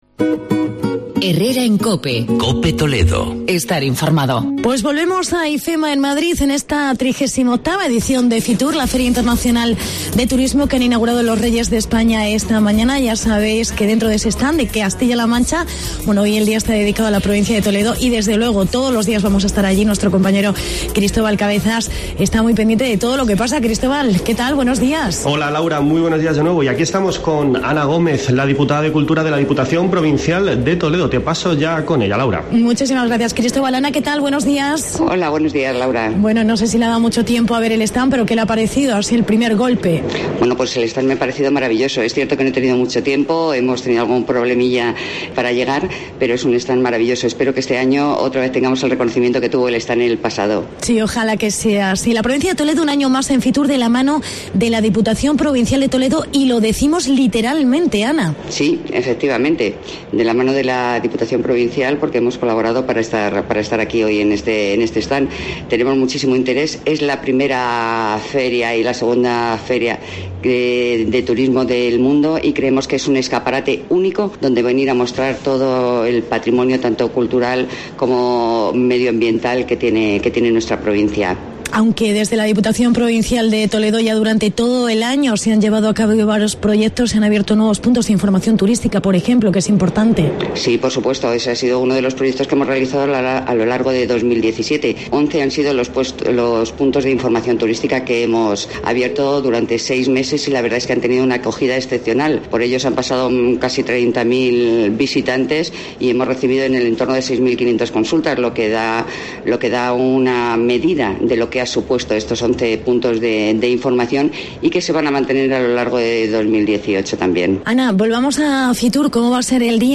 Entrevista con la diputada de cultura: Ana Gómez